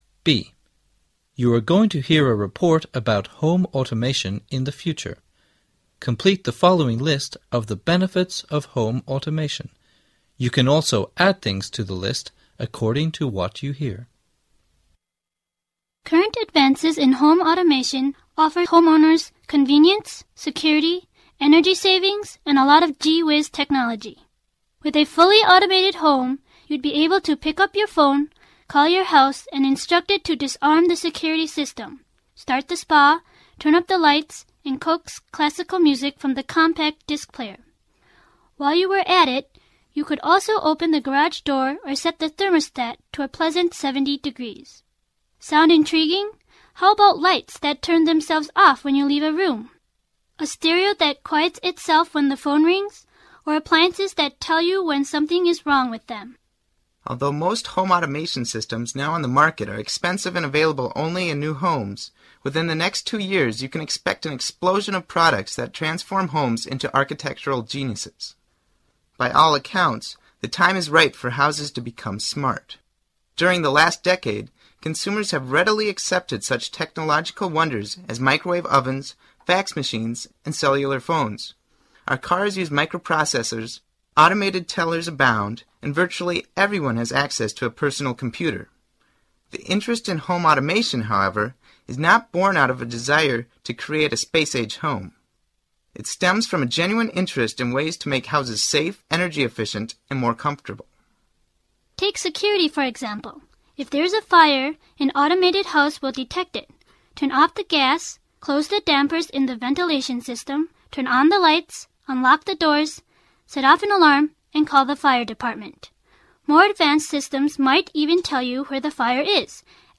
B. You're going to hear a report about home automation in the future, complete the following list of the benefits of home automation.